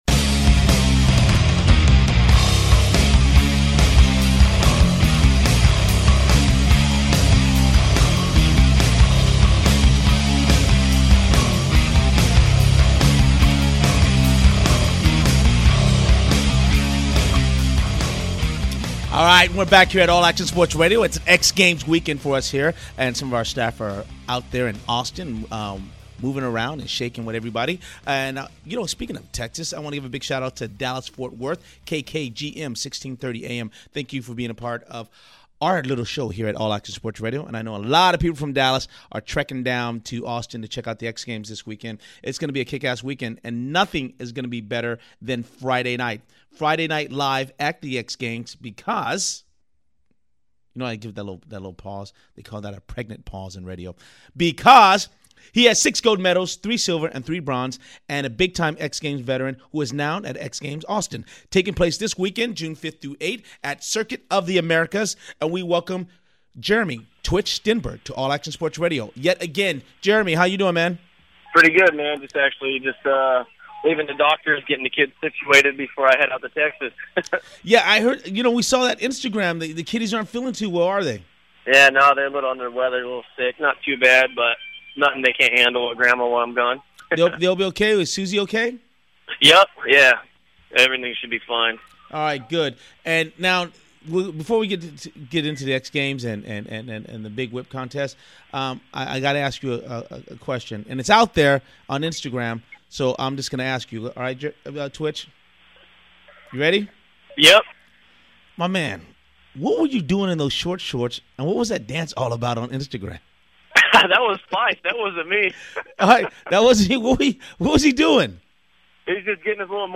AASR Athlete Interview with Jeremy "Twitch" Stenberg